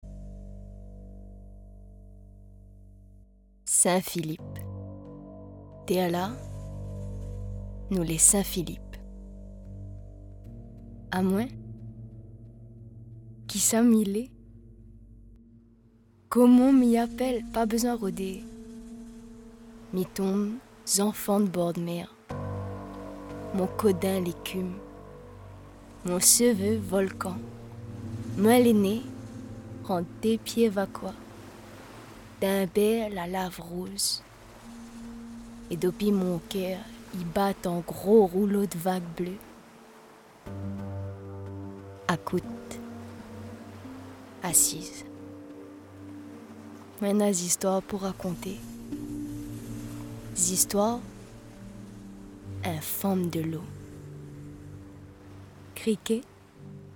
Voix off créole réunionnais